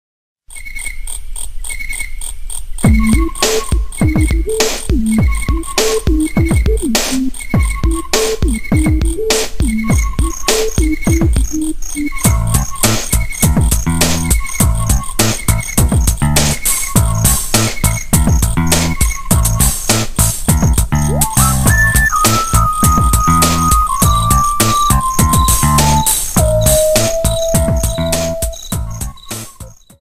Music for the outdoor portion